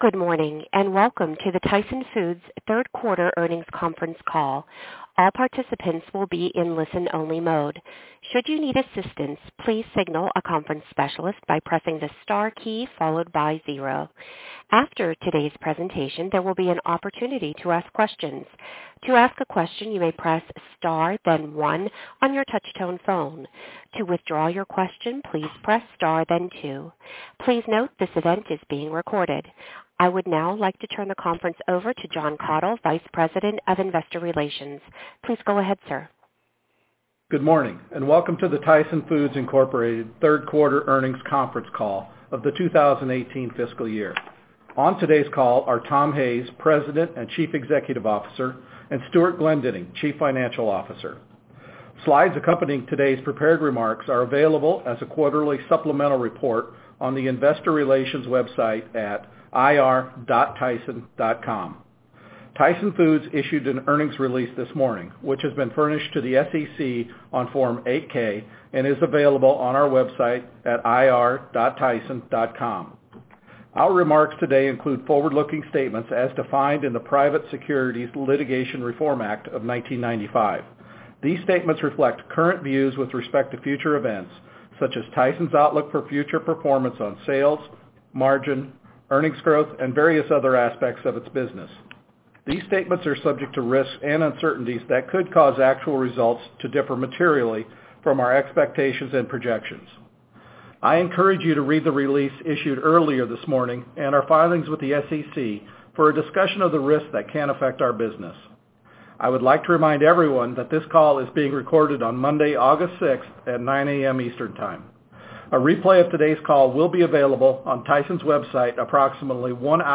Tyson Foods Inc. - Q3 2018 Tyson Foods Earnings Conference Call